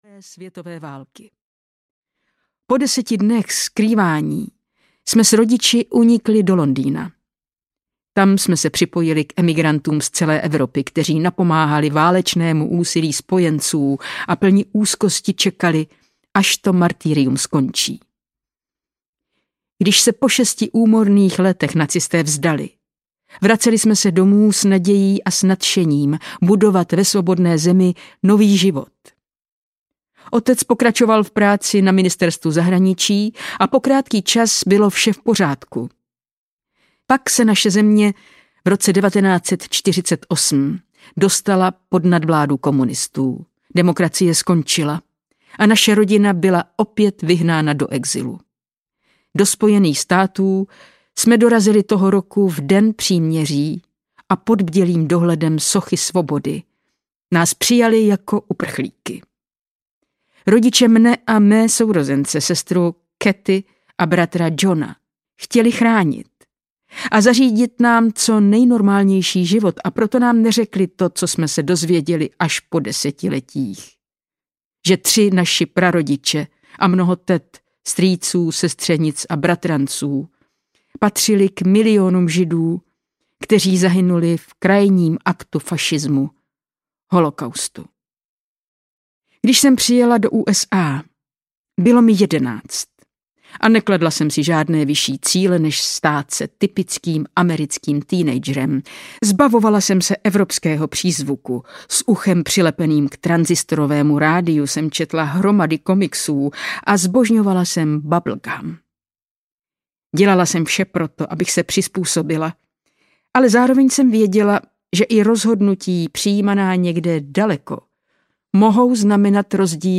Fašismus audiokniha
Ukázka z knihy
• InterpretTáňa Fišerová